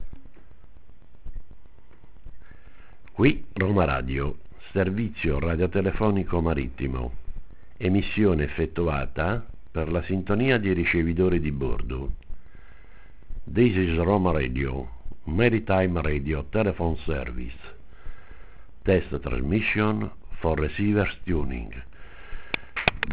iarvoice.WAV